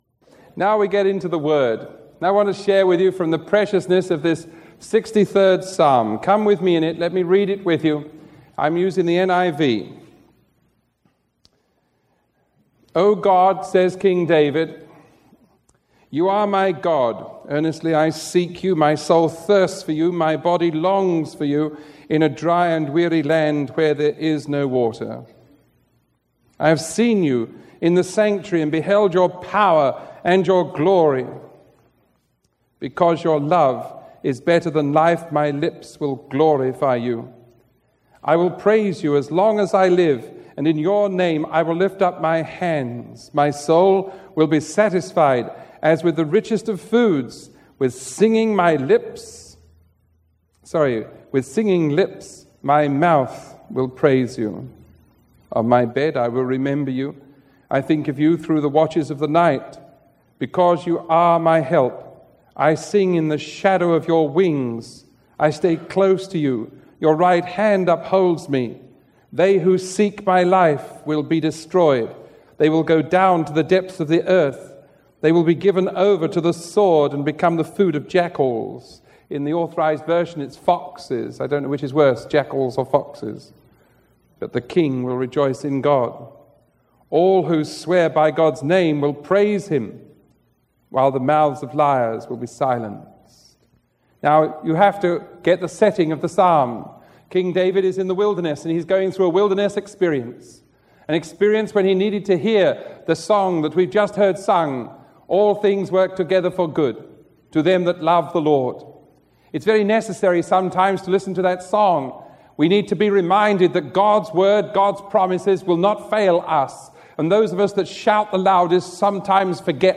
Sermon 1037E recorded on November 17, 1985 teaching from Psalm 63:1-11 – The Wilderness Experience.